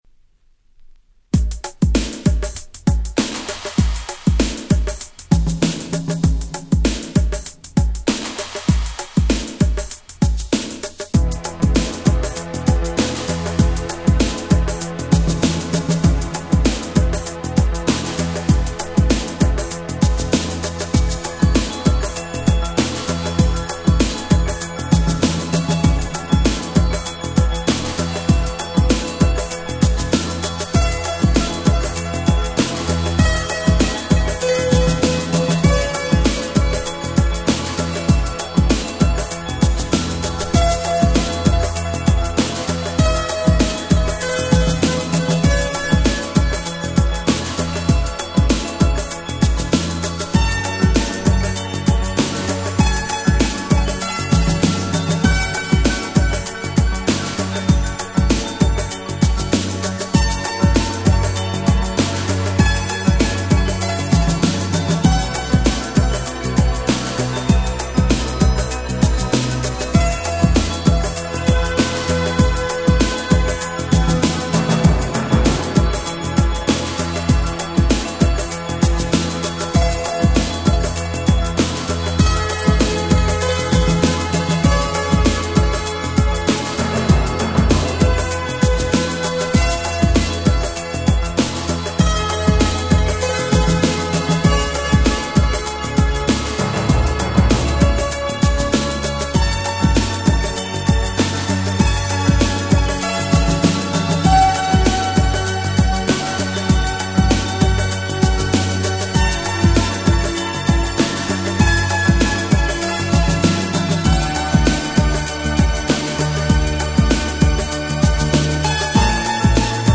91' Nice Ground Beat !!